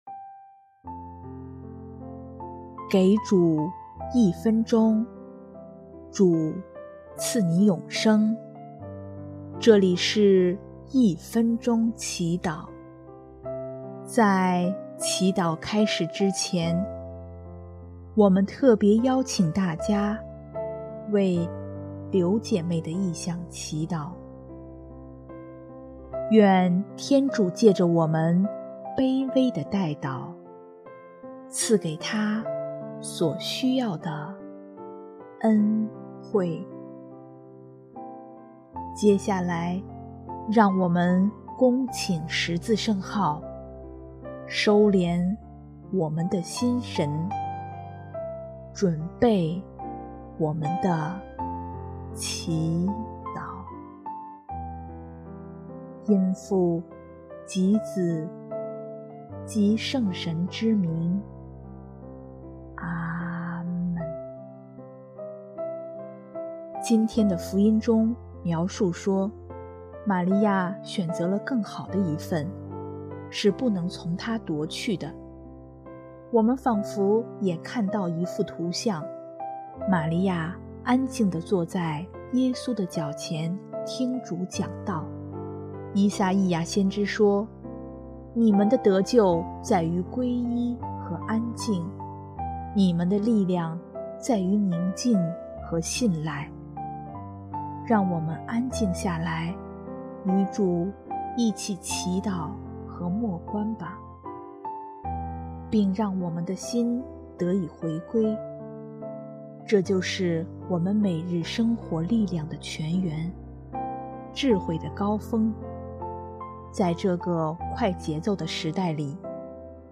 首页 / 祈祷/ 一分钟祈祷
音乐：第一届华语圣歌大赛参赛歌曲《静与动》